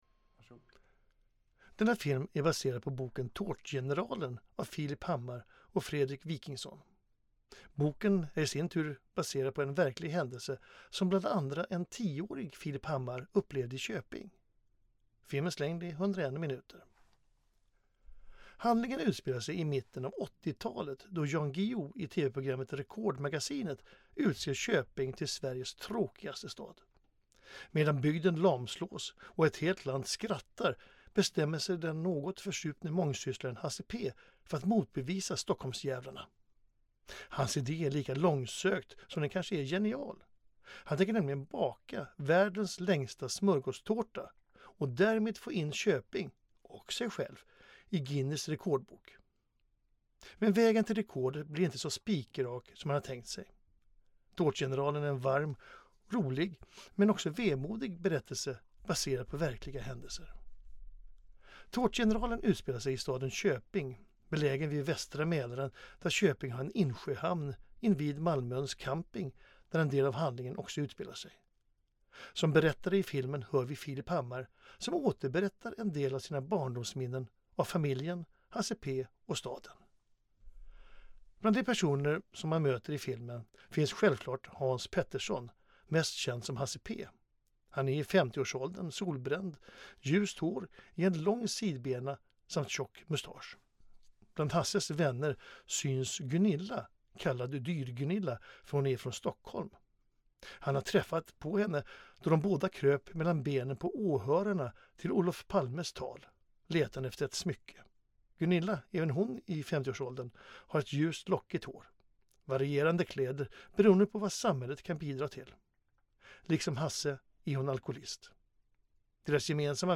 Ett kort inslag från filmen, syntolkat.